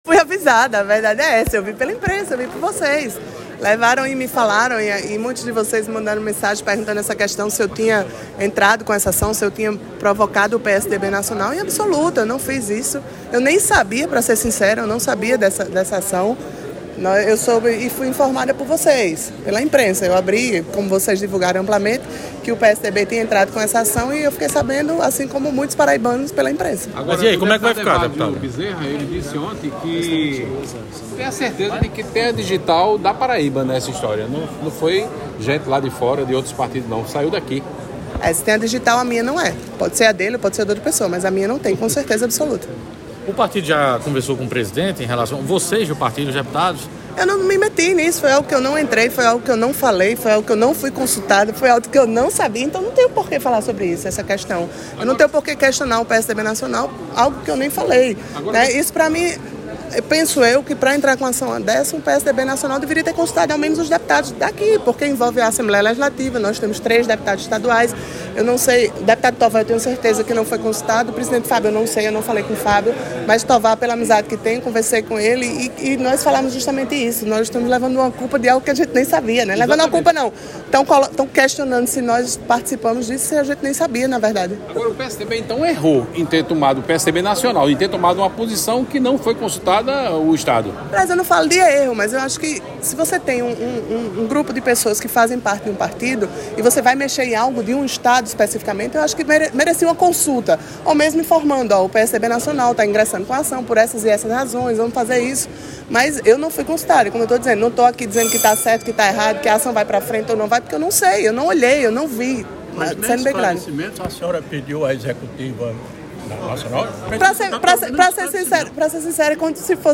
Abaixo a fala da deputada Camila Toscano para a reportagem do Portal PautaPB.